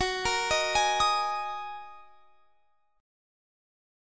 Listen to F#6add9 strummed